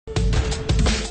Play, download and share Short Cool Music original sound button!!!!
short-cool-music.mp3